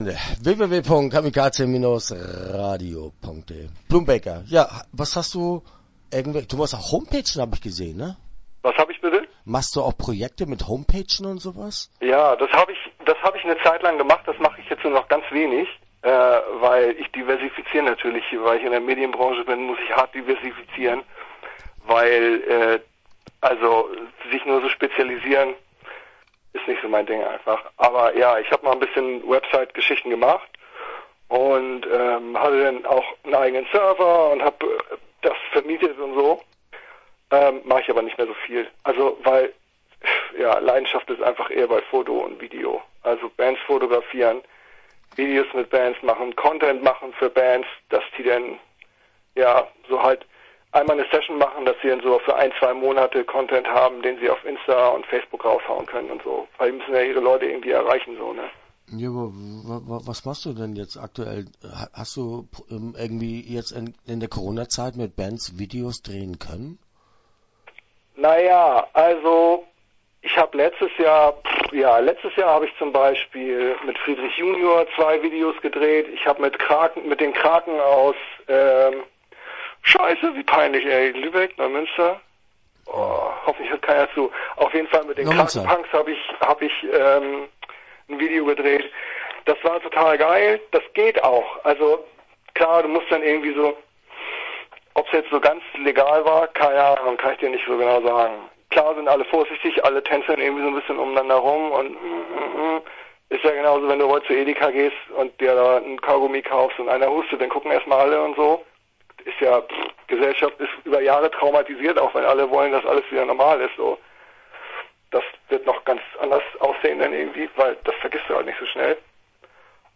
Blumbaker - Interview Teil 1 (10:11)